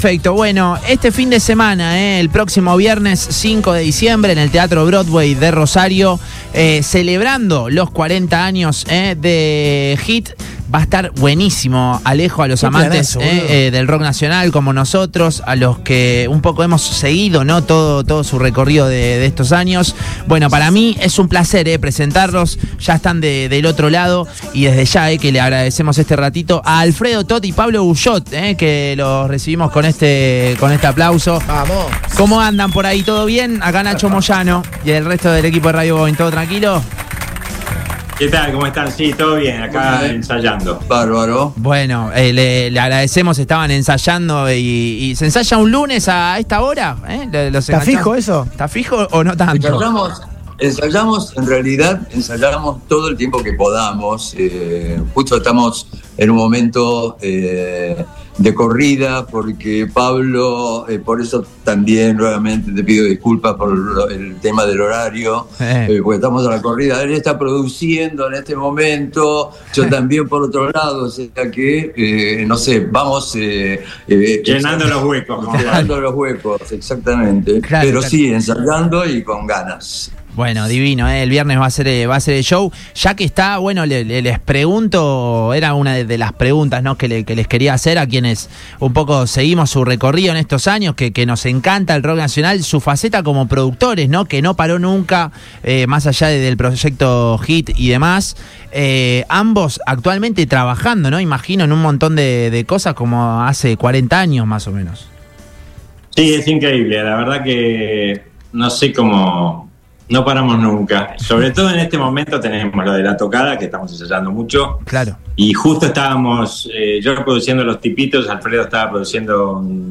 Dos leyendas vivientes del rock argentino —Pablo Guyot y Alfredo Toth, históricos integrantes de GIT— conversaron con el equipo de Boing y Sus Secuaces por Radio Boing y anticiparon lo que será el gran concierto del próximo viernes 5 de diciembre en el Teatro Broadway, en el marco de la celebración por los 40 años del grupo.